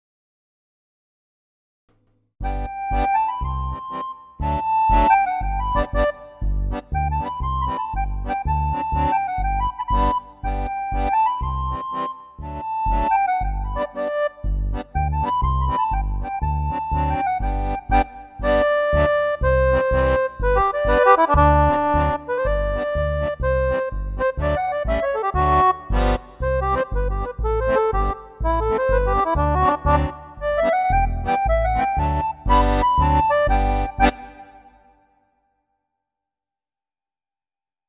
Das Stück soll mal in unserem Trio (Geige, Gitarre und Akko) eingespielt werden, Ihr hört ein Stück vermutlich aus dem Anfang des 18. Jahrhunderts, es bleibt nördlich, aber außerhalb von Deutschland, und Ihr hört nur den Akkordeonteil, eingespielt mit meinem Neuen.